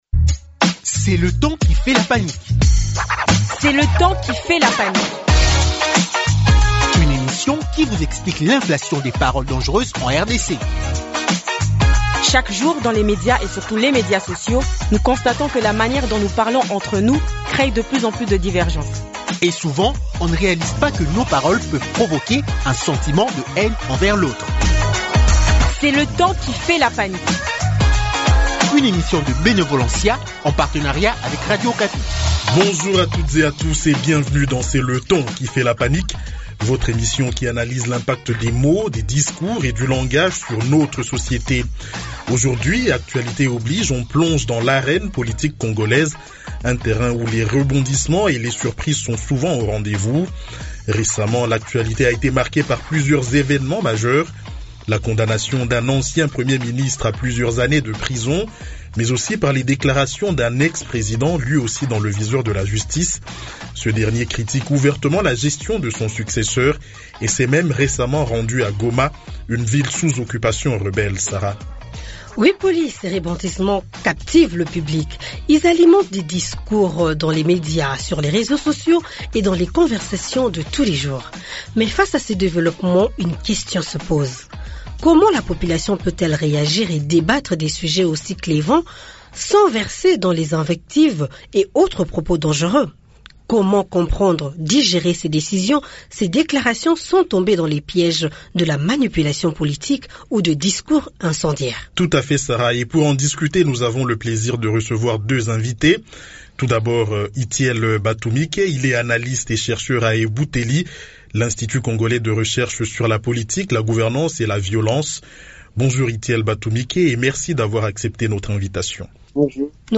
Pour en discuter, nous avons le plaisir de recevoir deux invités.